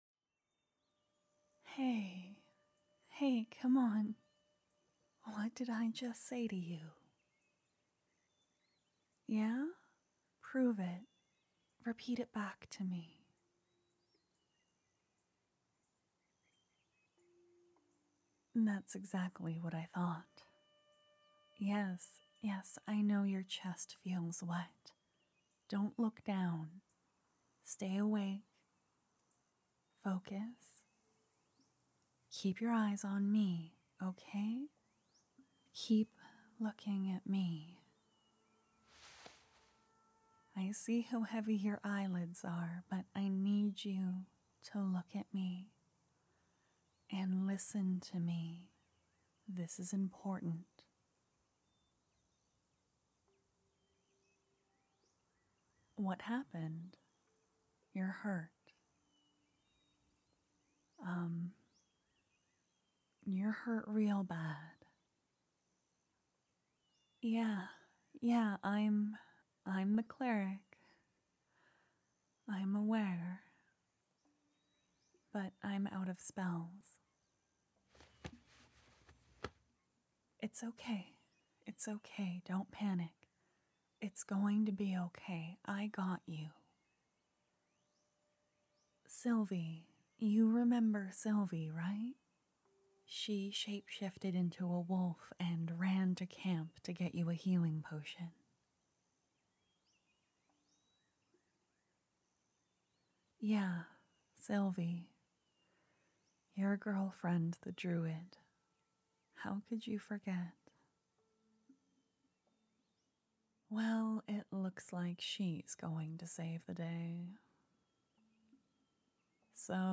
I feel your voice work is getting noticeably better.
It's the dumbest thing, but I started sitting down to record. I'm more comfortable and it's easier to emote~!